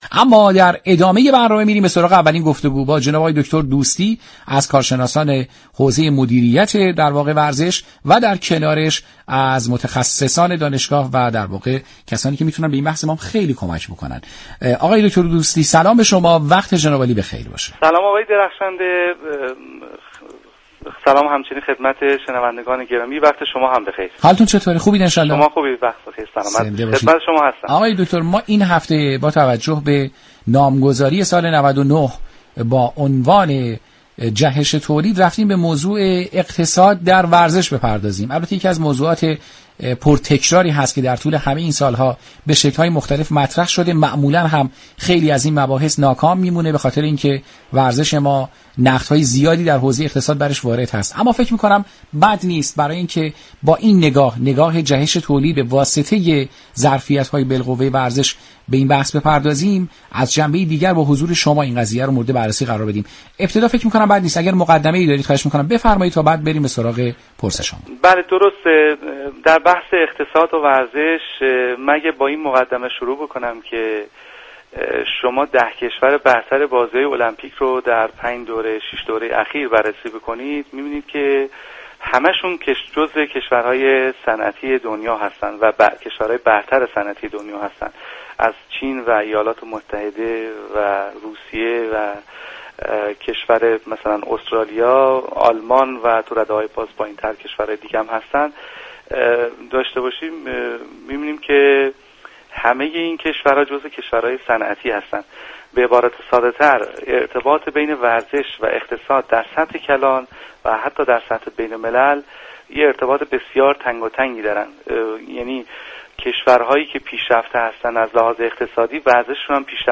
این برنامه زنده ساعت 12 هر روز بجز جمعه ها به مدت 60 دقیقه از شبكه رادیویی ورزش تقدیم شنوندگان می شود.